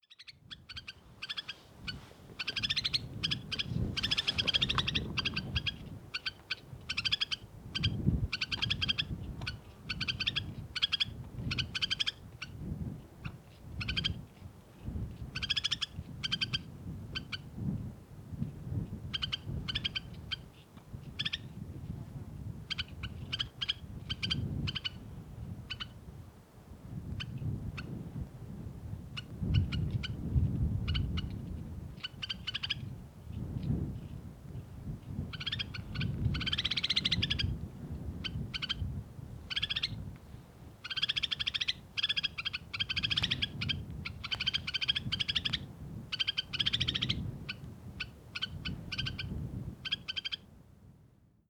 White browed Snowfinch
Carpodacus thura